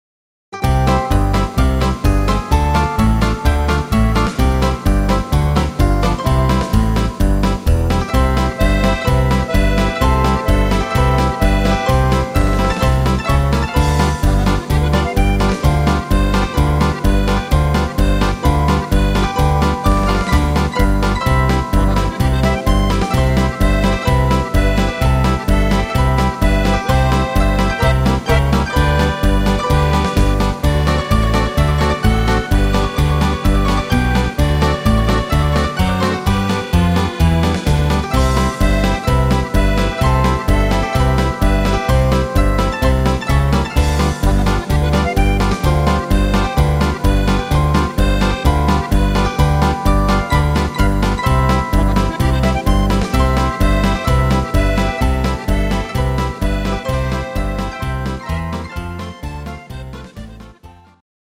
instr. Zither
Rhythmus  Marsch
Art  Instrumental Allerlei, Volkstümlich